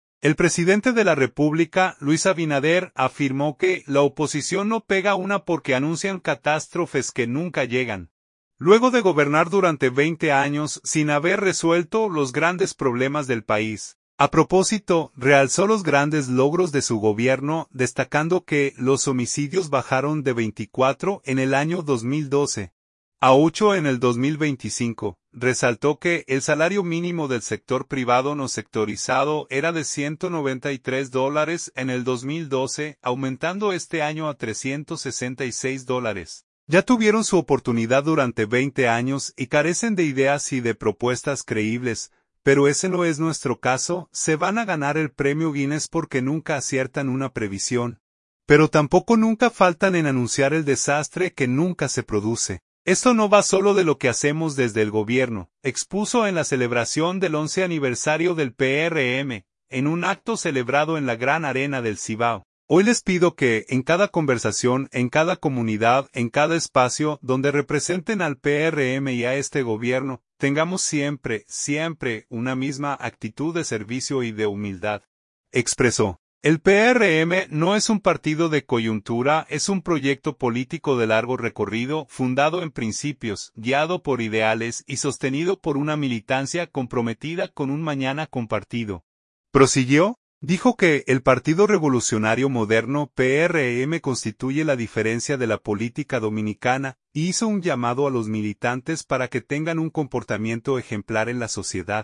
“Ya tuvieron su oportunidad durante 20 años y carecen de ideas y de propuestas creíbles, pero ese no es nuestro caso… se van a ganar el Premio Guinness porque nunca aciertan una previsión, pero tampoco nunca faltan en anunciar el desastre que nunca se produce. Esto no va solo de lo que hacemos desde el Gobierno“, expuso en la celebración del 11 aniversario del PRM, en un acto celebrado en la Gran Arena del Cibao.